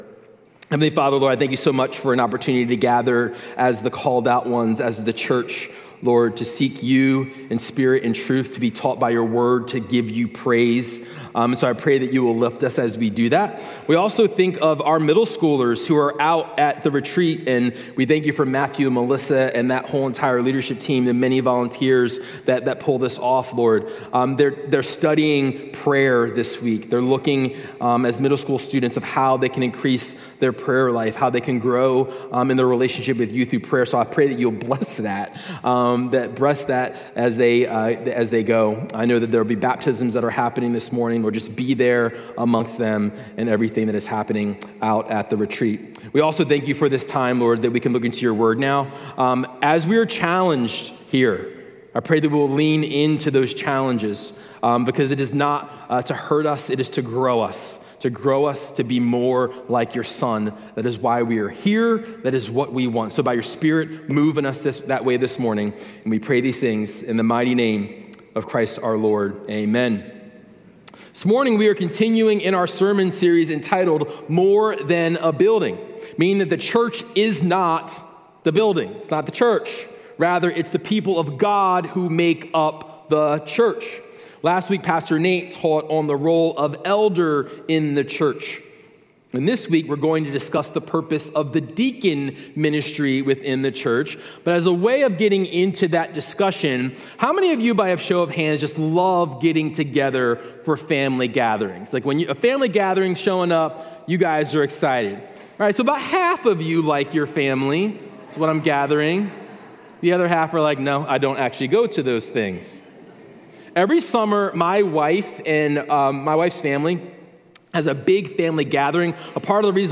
A message from the series "More than a Building."